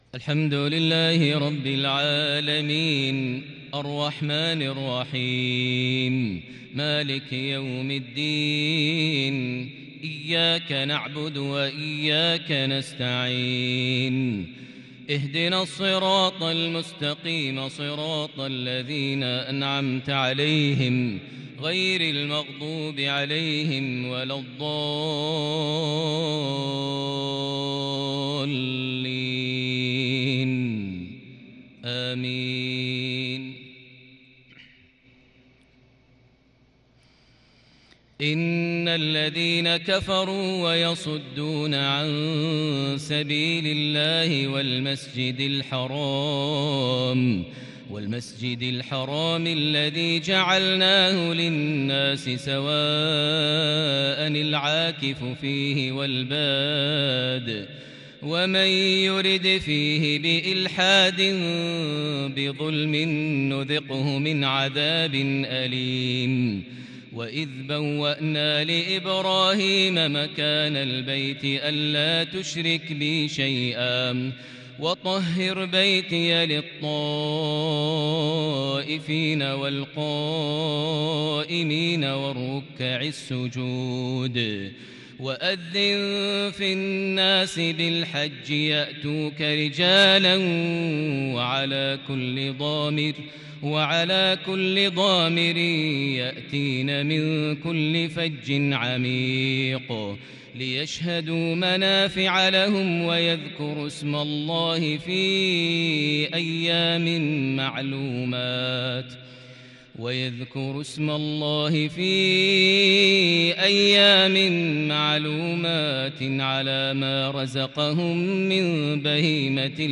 (وأذن في الناس بالحج) عشائية فريدة بالكرد الفذ من سورة الحج (25-37) | السبت 30 ذو القعدة 1442هـ > 1442 هـ > الفروض - تلاوات ماهر المعيقلي